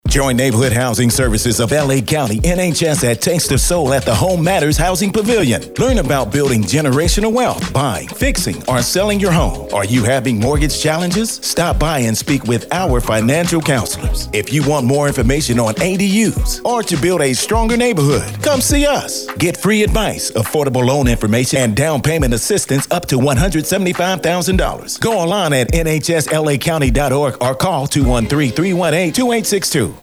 NHS Taste of Soul Radio Spot Promos